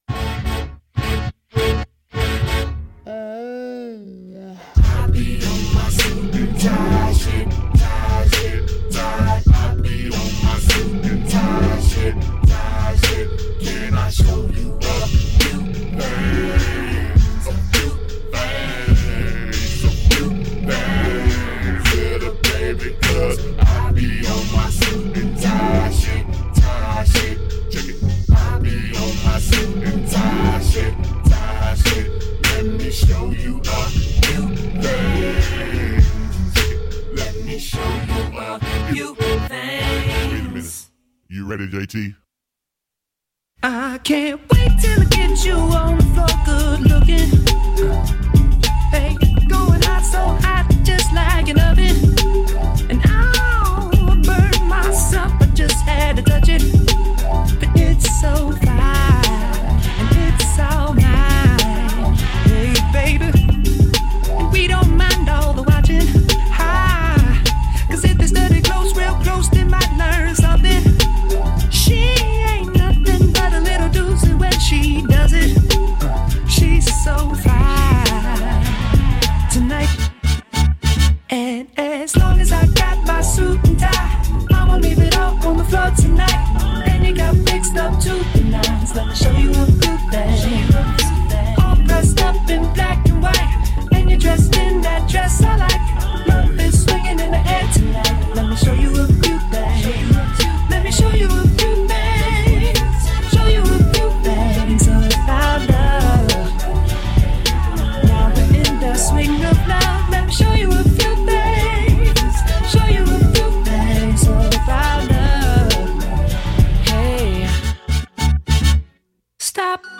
actual R&B-flavored dance music
(Note: be sure to listen beyond the 40-second intro)